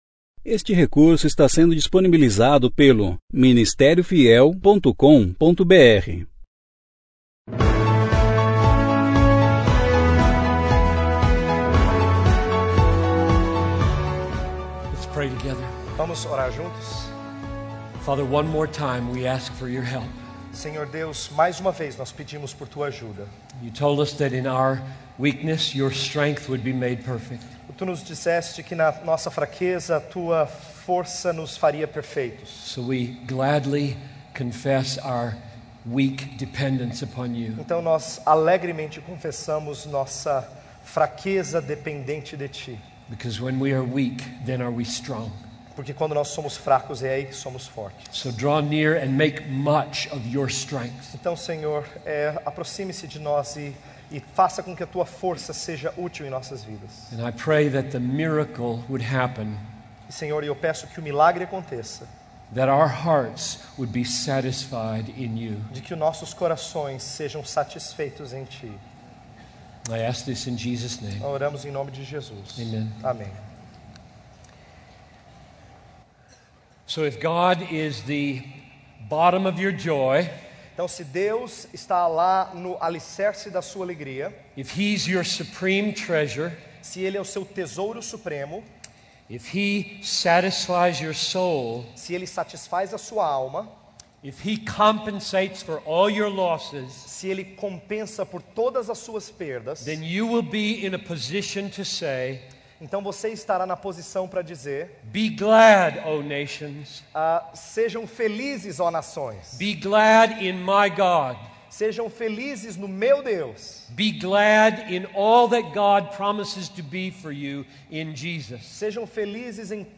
Conferência: 27ª Conferência Fiel para Pastores e Líderes Tema: Evangelização e Missões - Is 52.7 An
Preletor: John Piper